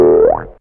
Boink.wav